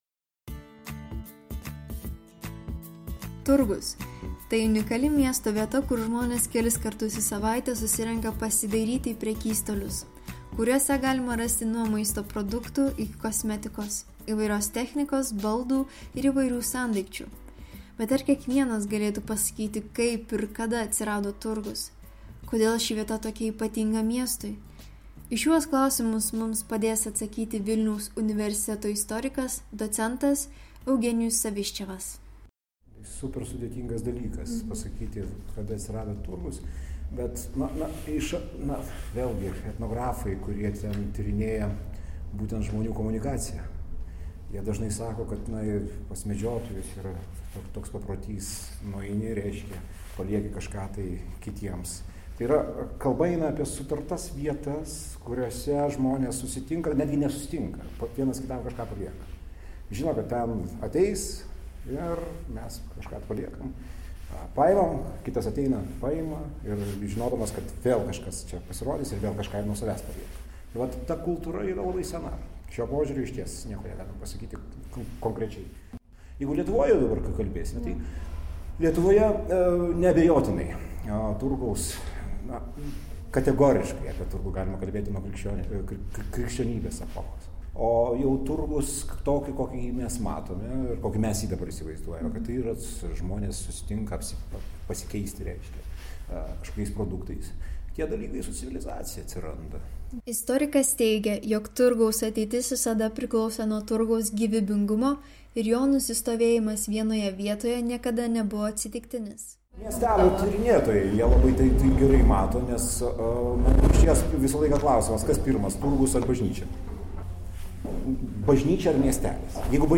Praeivių bei artimųjų klausėme, ką jie žino apie turgaviečių atsiradimą.